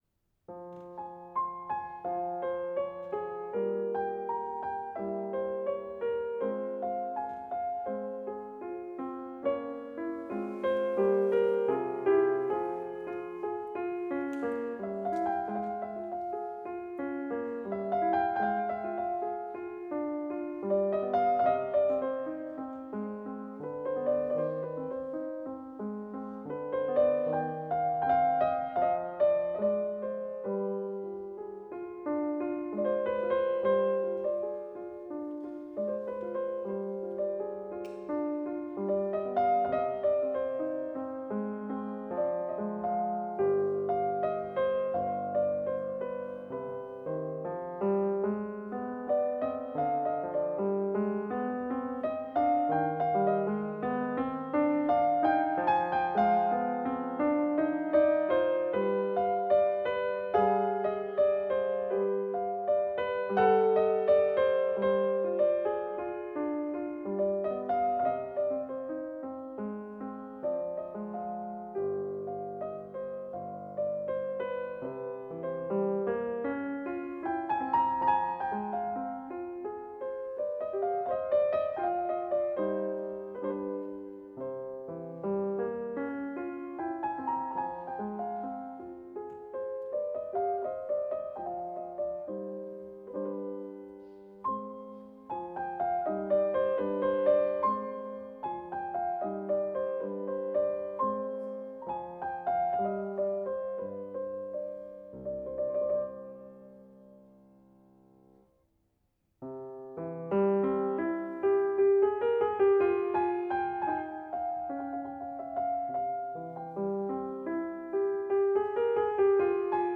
1. Domenico Scarlatti Sonate f-Moll K466 - Andante moderato
(Konzertmitschnitt 9.3.2007 St.Peterskirche Zürich)